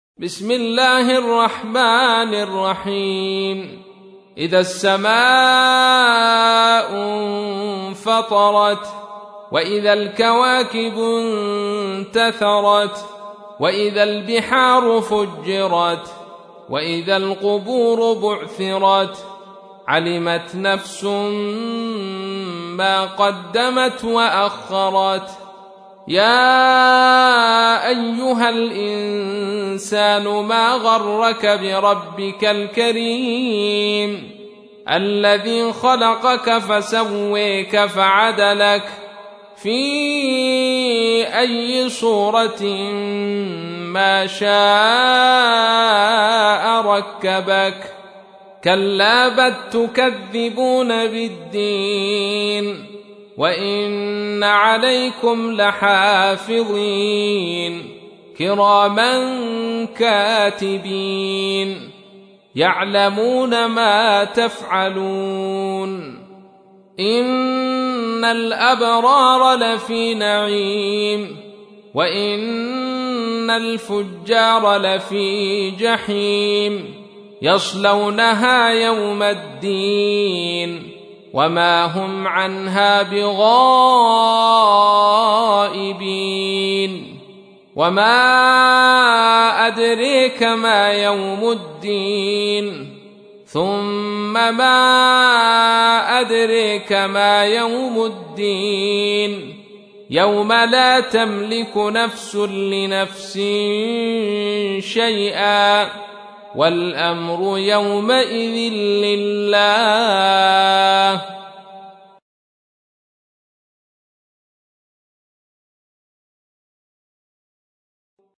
تحميل : 82. سورة الانفطار / القارئ عبد الرشيد صوفي / القرآن الكريم / موقع يا حسين